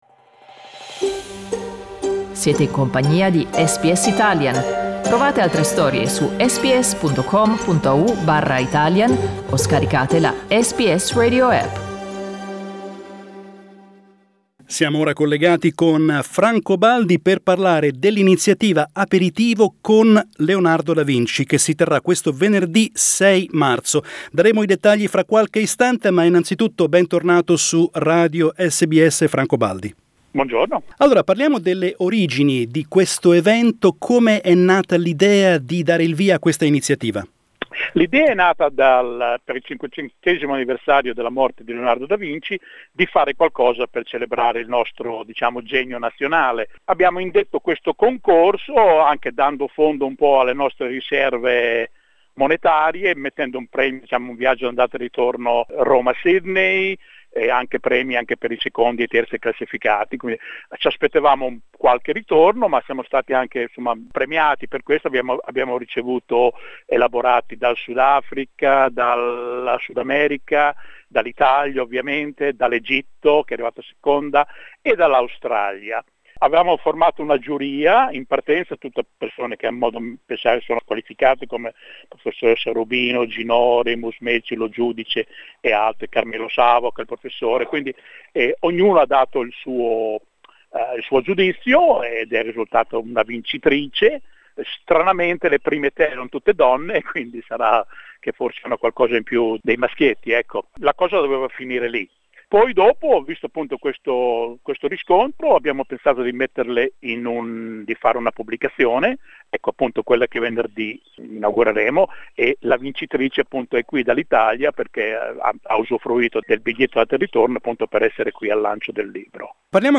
Our interviews